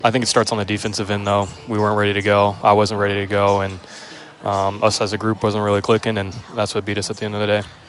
That's Iowa coach Ben McCollum.